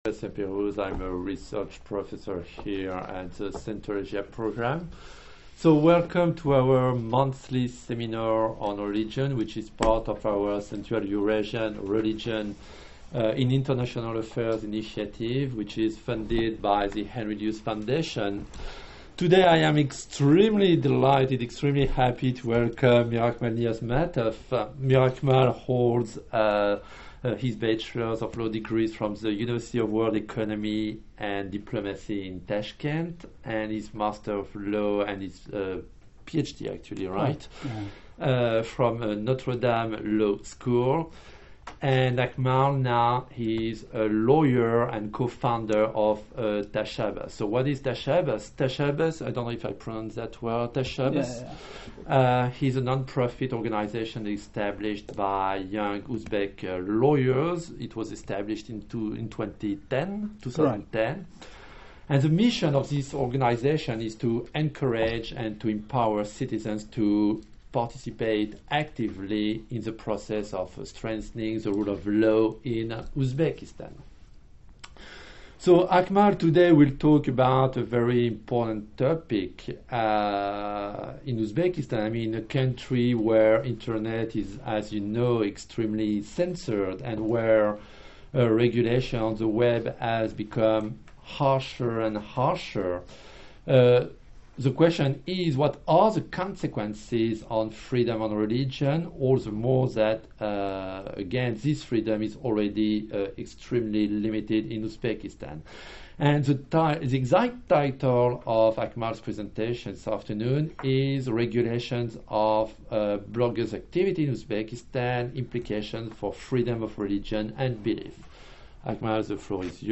Regulation of blogger's activity in Uzbekistan. Panel at GW, Central Asia program. Part-III